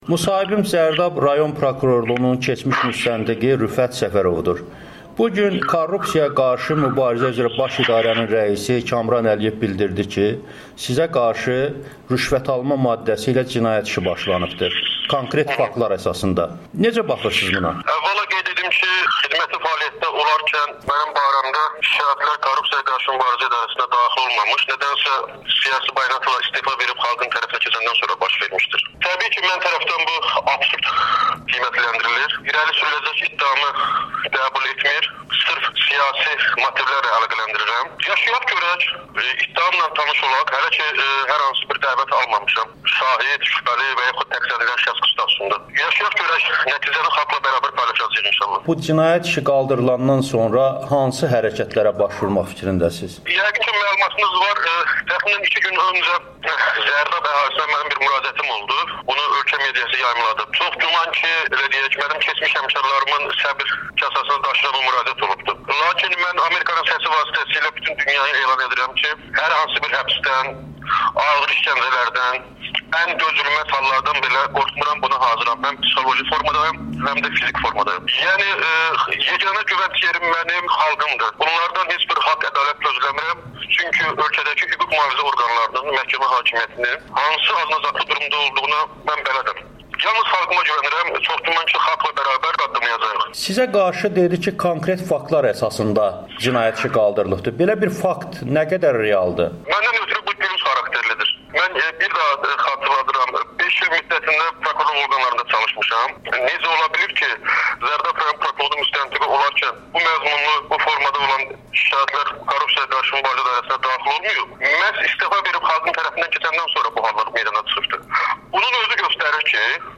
Audio-müsahibə